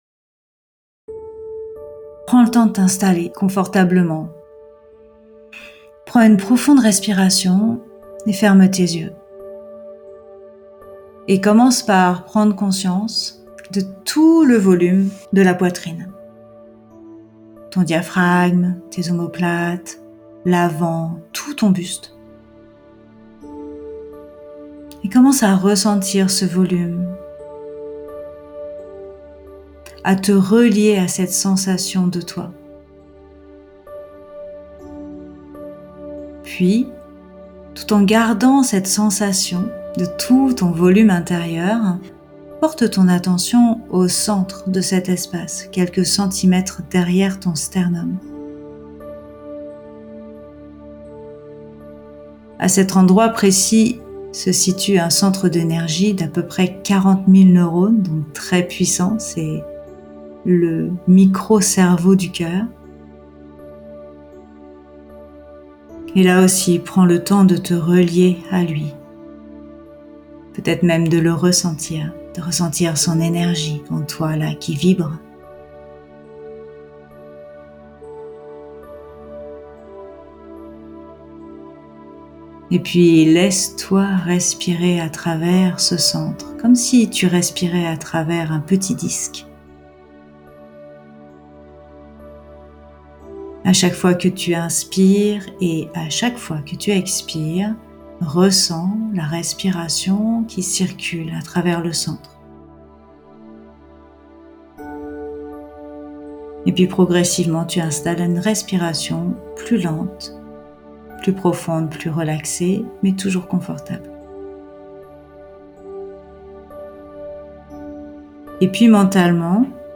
Mediation-alignement.mp3